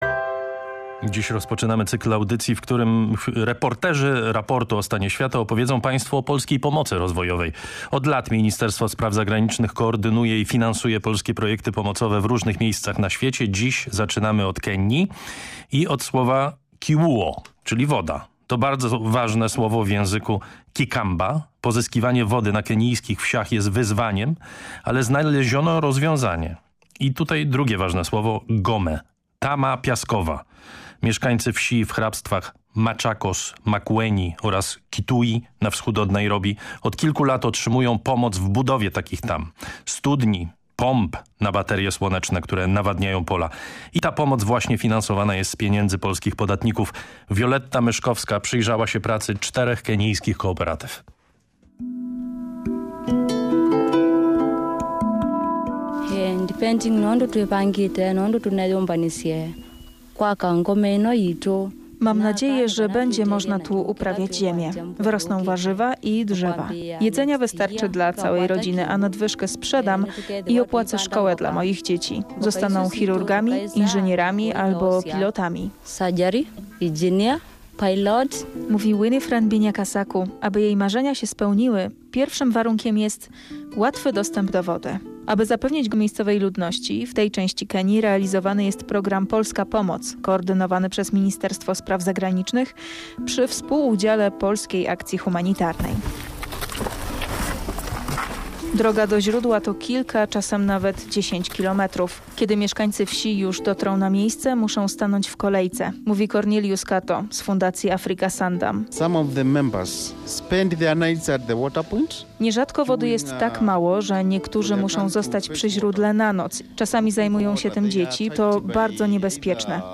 Reportaż oraz fotoleracja na stronach Raportu o stanie świata Programu Trzeciego PR